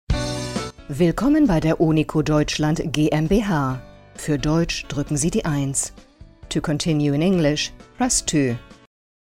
on hold voice recordings
Female Voice Talent